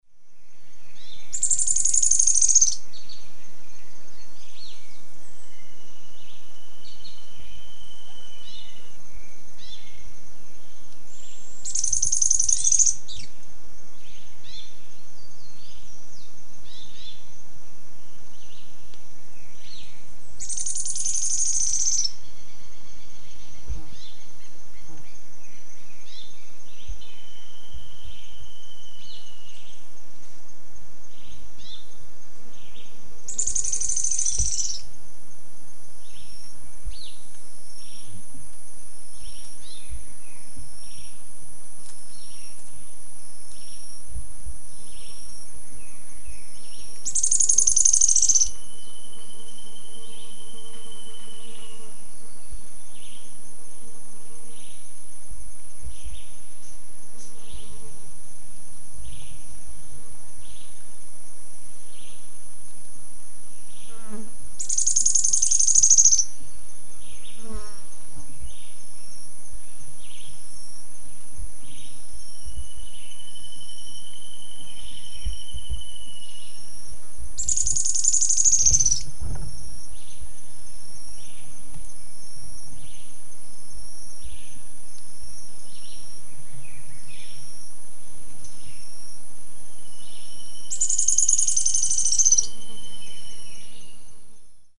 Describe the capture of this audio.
CranioleucaobsoletaSanta Ines23NOV01MVQZsong.mp3